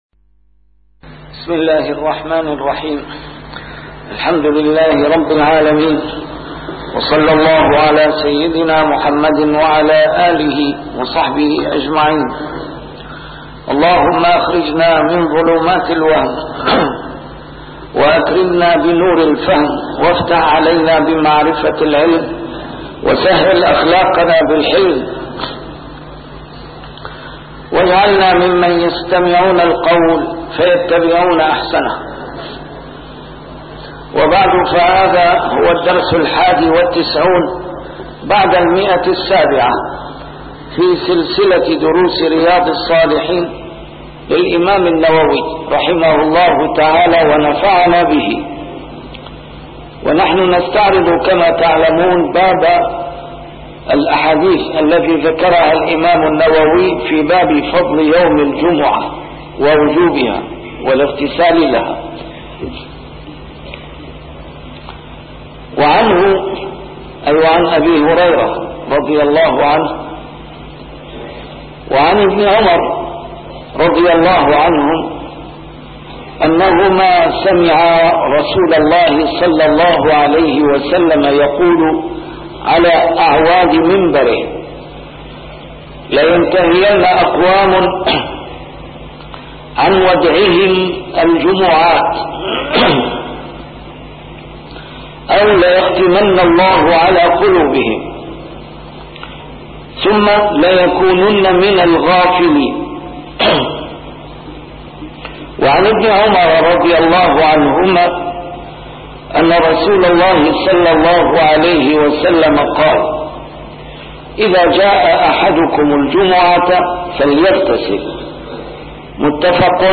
A MARTYR SCHOLAR: IMAM MUHAMMAD SAEED RAMADAN AL-BOUTI - الدروس العلمية - شرح كتاب رياض الصالحين - 791- شرح رياض الصالحين: يوم الجمعة